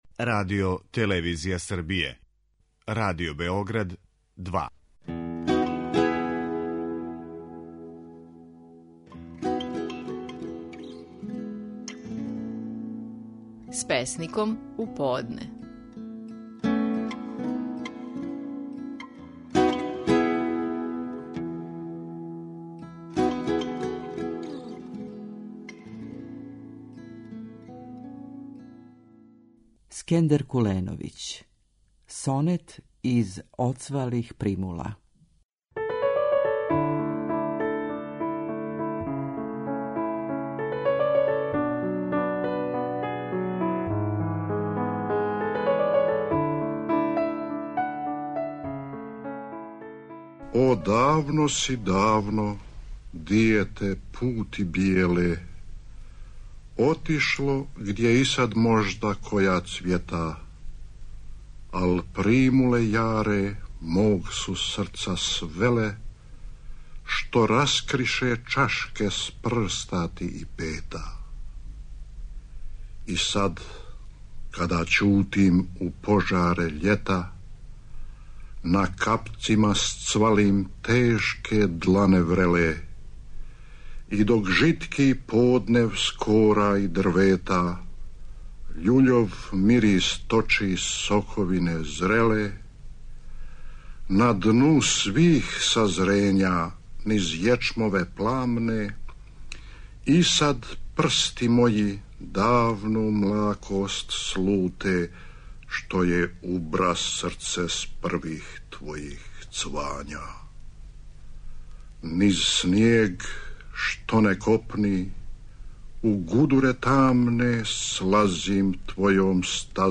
Наши најпознатији песници говоре своје стихове
Сонет из "Оцвалих примула", говори песник Скендер Куленовић.